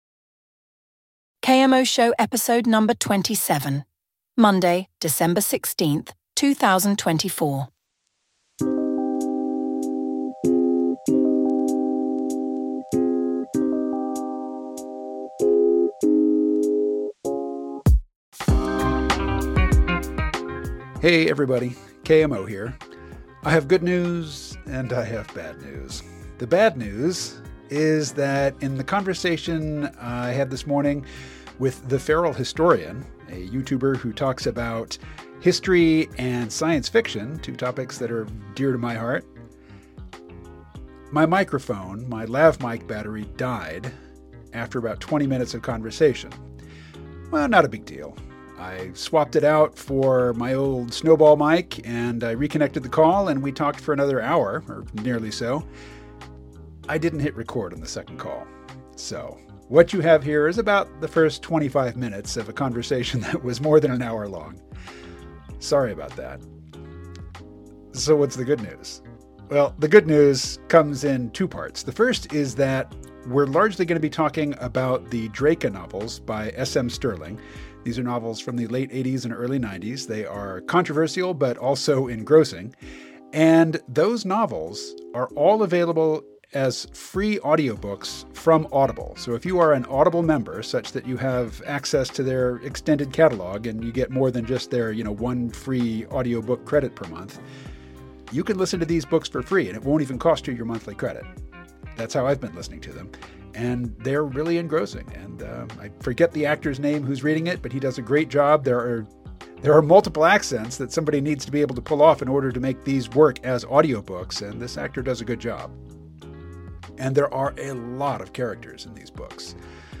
While only 25 minutes of their hour-plus conversation survived due to technical difficulties, they explore how these books examine the creation of sustainable systems of oppression and the psychological dynamics of slave societies. The discussion touches on how the Draka maintain control through careful management of violence, their pragmatic approach to social organization, and their complex relationship with their enslaved population.